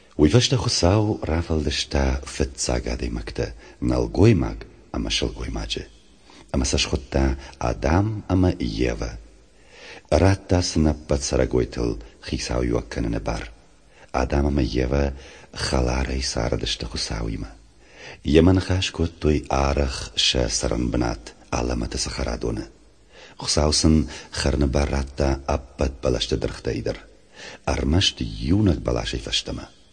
29 September 2013 at 1:27 pm Pronunciation of Eve as [‘jeva] probably shows into direction of Russian influence.
30 September 2013 at 2:41 am There’s no nasal diphtong “ão” in the recording. I could only hear a regular “ao” diphtong at the beginning.